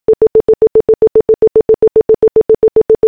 소리의 속도로 표현한 빛의 속력, 매 삐 소리마다 빛이 적도를 한 바퀴 돈다.